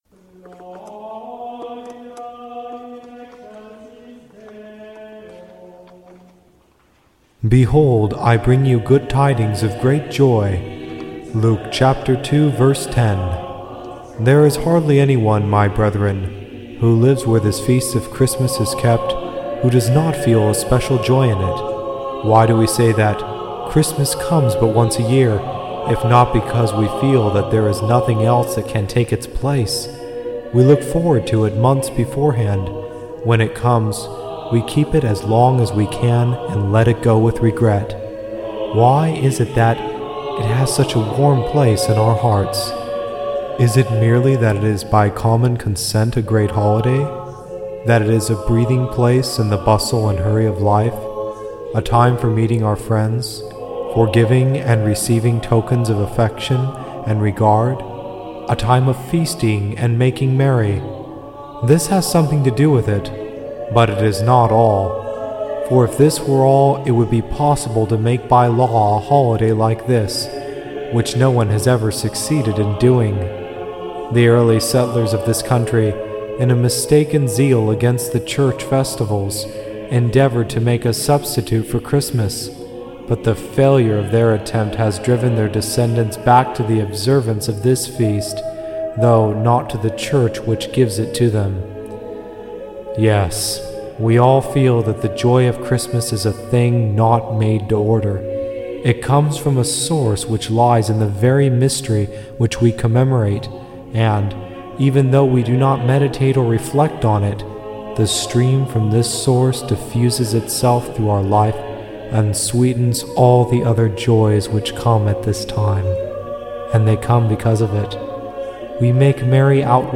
Catholic Homily Sunday in Octave of Christmas
Music used under attribution license